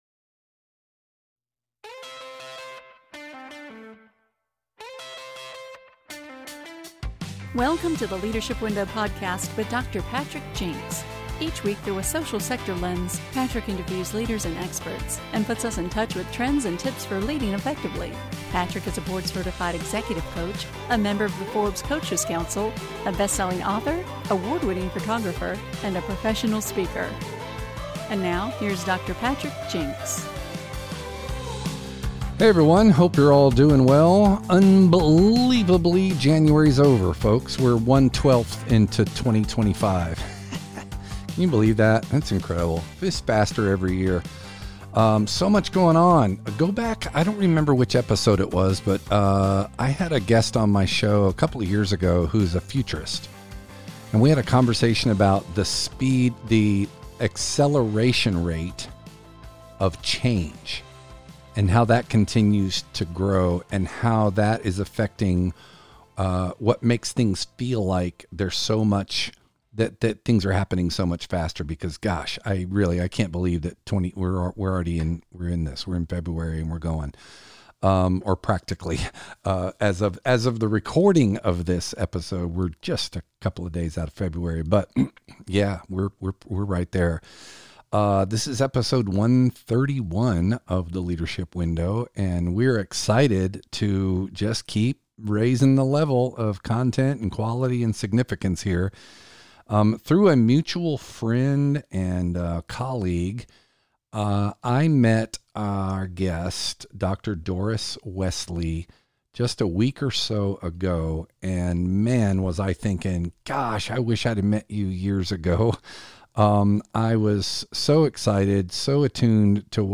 chats